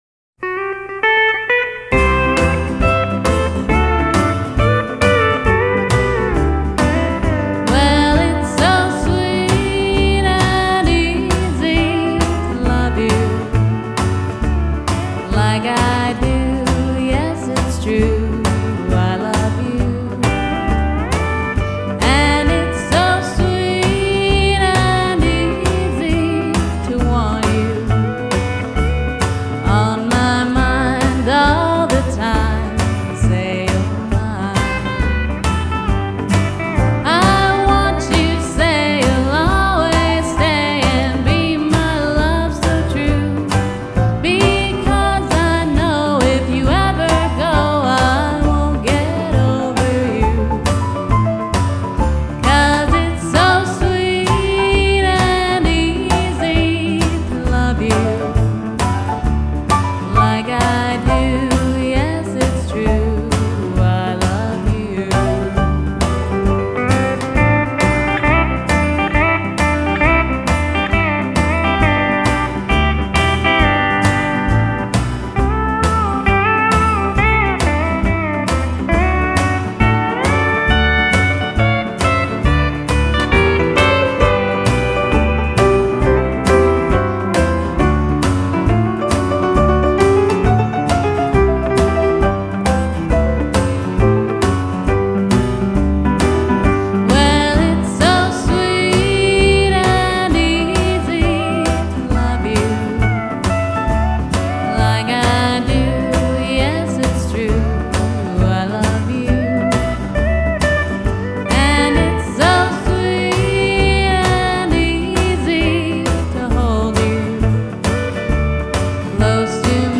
grounded in the Country and Rockabilly of the '50's